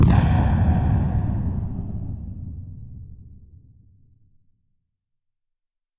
Sci Fi Explosion 02.wav